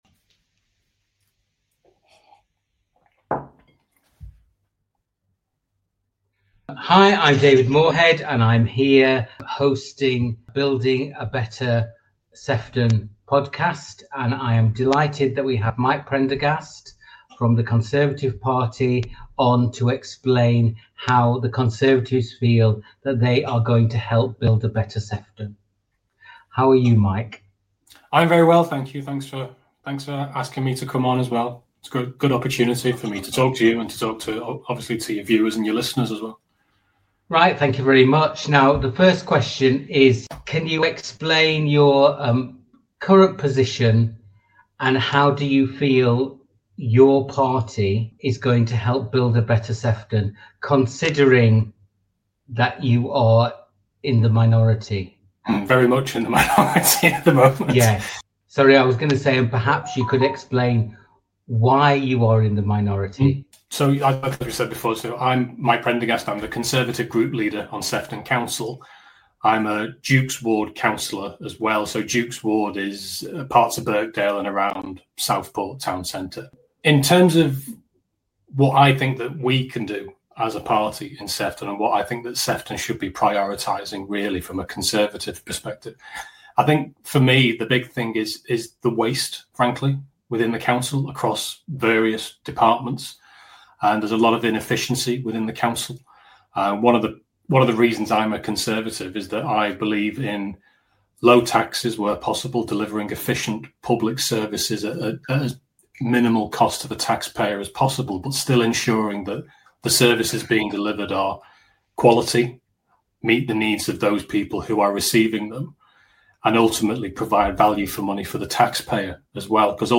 Today at Building a Better Sefton, we speak to Mike Prendergast, the leader of the Conservative Group in Sefton Council. Mike is a councillor in the Dukes Ward of Birkdale and Southport.